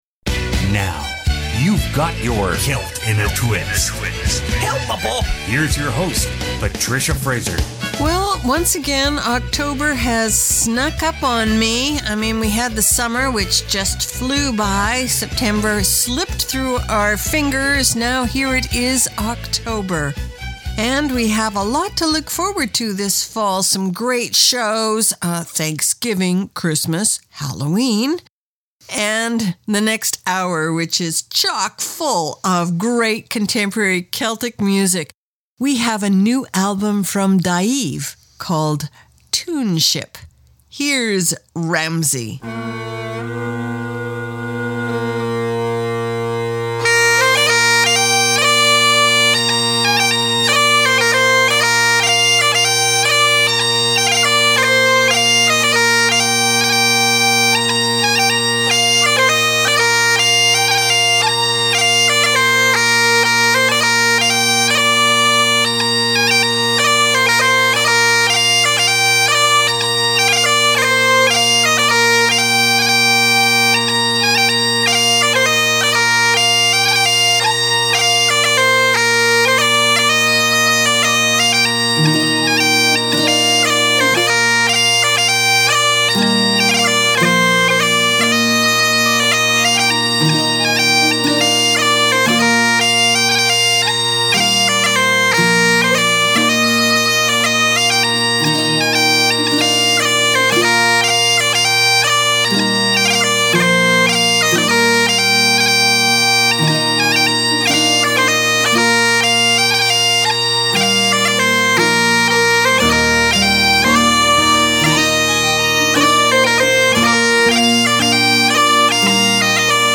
Canada's Contemporary Celtic Radio Hour
File Information Listen (h:mm:ss) 0:59:22 Celt In A Twist October 5 2014 Download (5) Celt_In_A_Twist_October_05_2014.mp3 71,235k 0kbps Stereo Comments: She's a little bit Celtic, a little bit Rock n Roll!